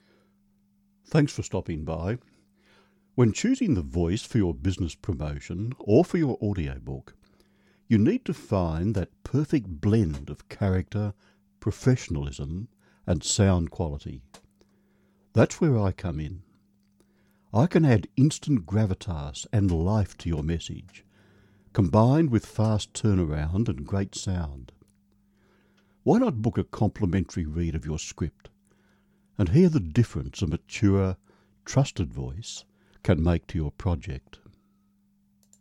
Reliable, Confident and Credible Mature Male Voiceover
A trusted, natural voice that reassures without the hard sell.
a mature, trusted voice
Welcome & Introduction
Voice Sample